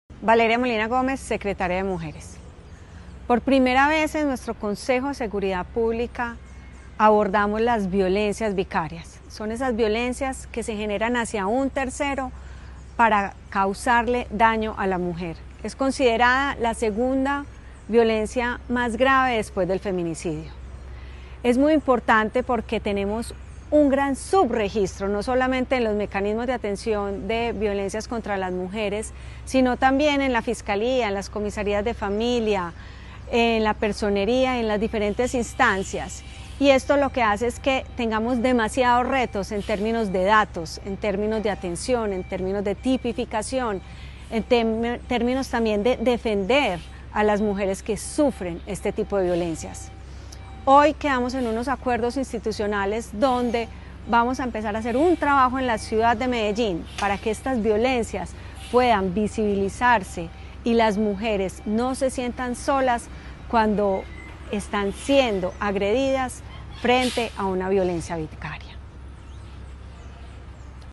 Declaraciones-secretaria-de-las-Mujeres-de-Medellin-Valeria-Molina-Gomez.mp3